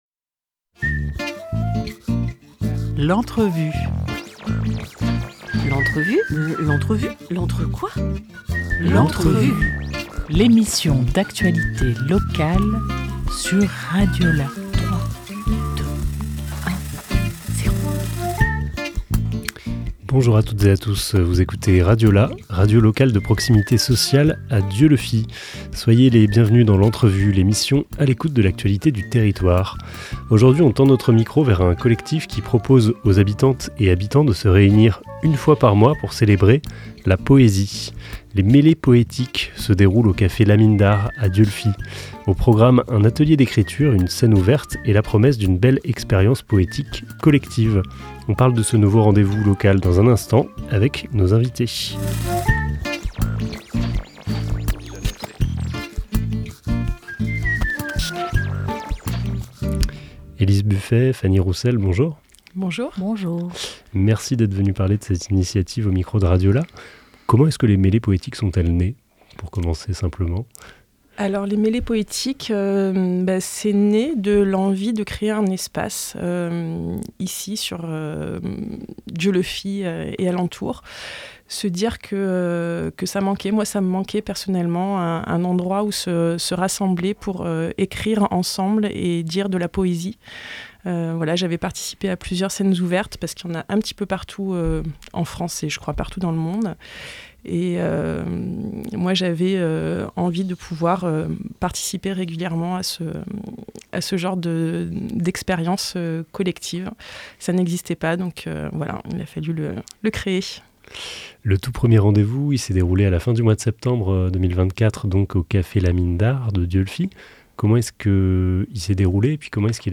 8 octobre 2024 11:39 | Interview